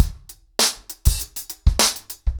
DrumkitRavage-100BPM_1.7.wav